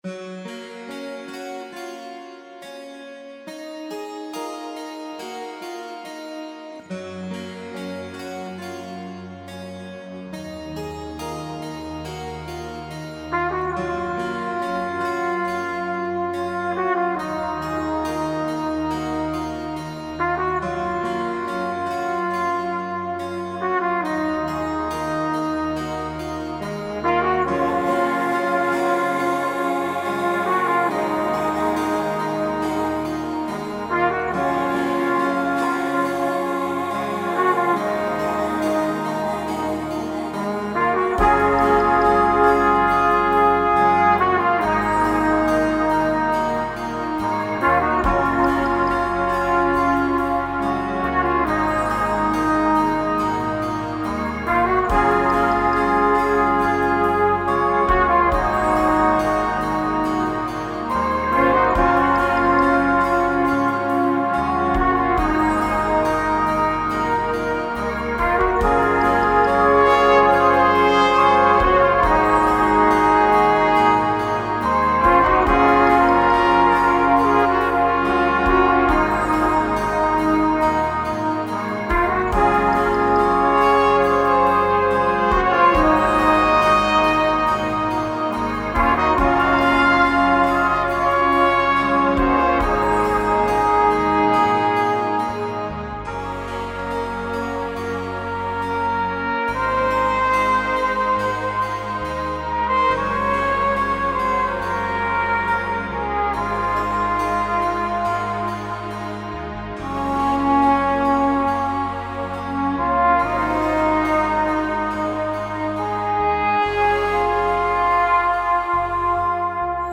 flugelhorn or trumpet